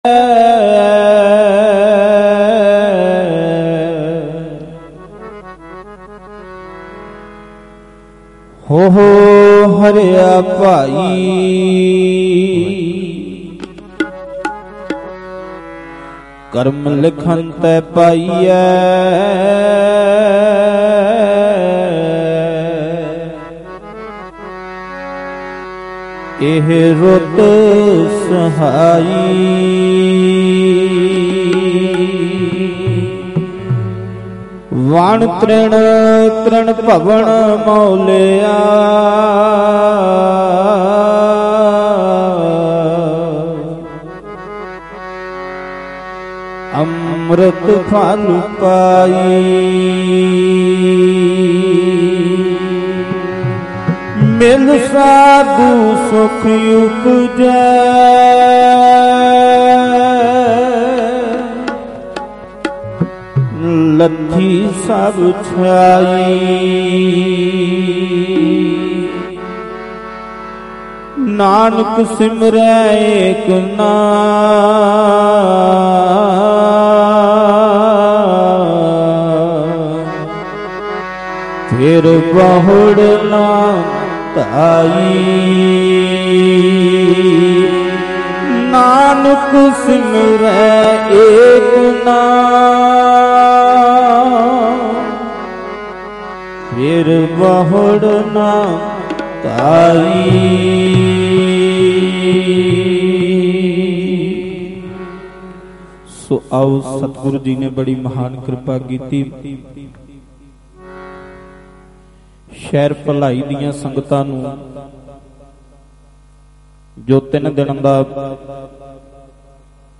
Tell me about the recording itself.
Live gurmat samagam Bhilai Chhattisgarh 15 jan 2026